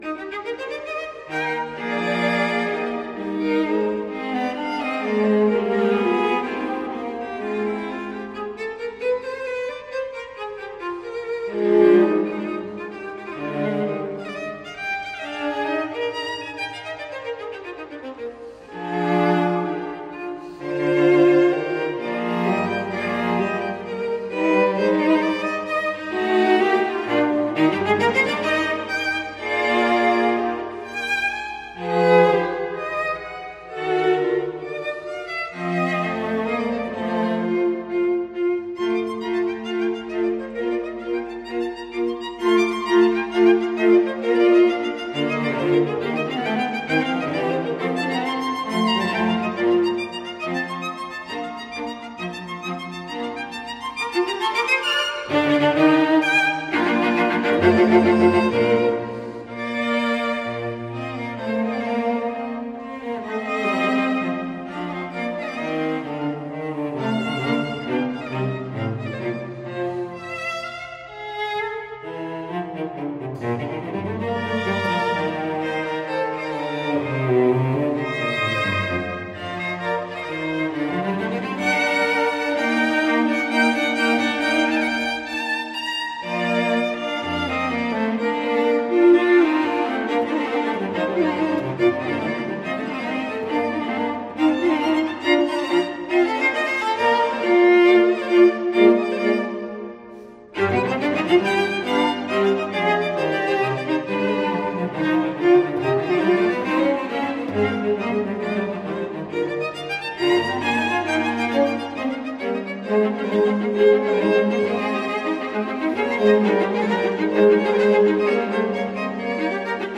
Soundbite Movt 1